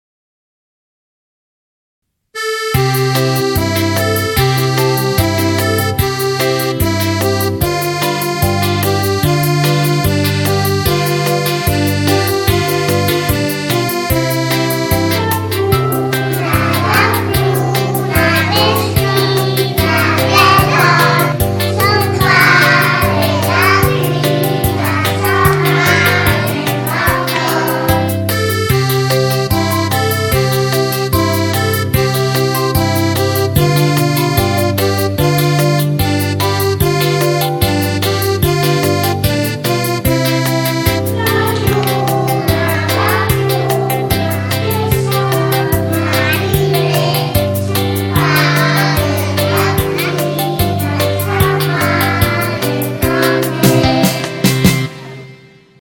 Us deixo penjat al bloc un nou episodi del CD de Cançons Populars que hem enregistrat a l’escola.
Els alumnes de P3 canten “La lluna, la pruna” (els haguessiu hagut de veure, perquè realment feien una careta per dormir els bebés imaginaris que portaven als braços…)